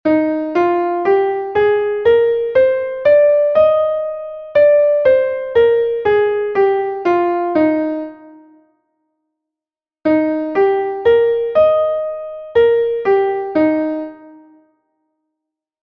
Entoación a capella
Escala e arpexio:
escala_arpegio_mib_M.mp3